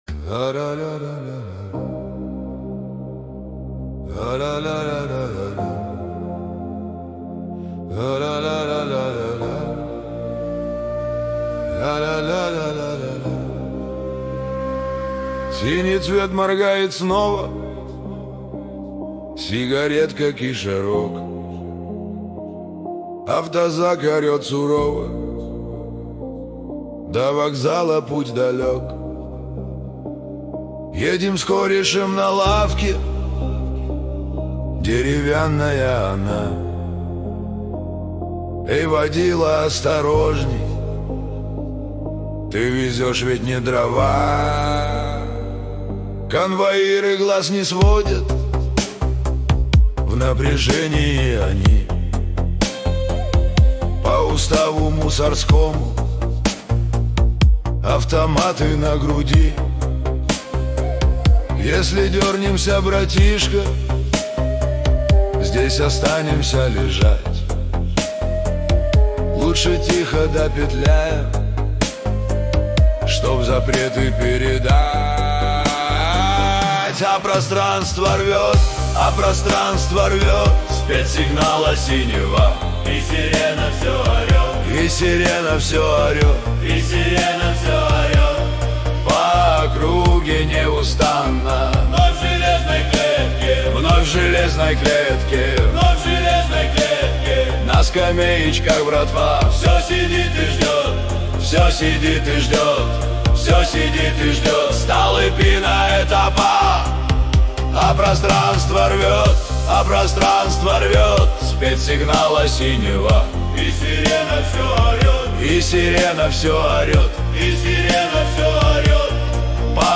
Русские поп песни